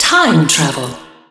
time_travel.wav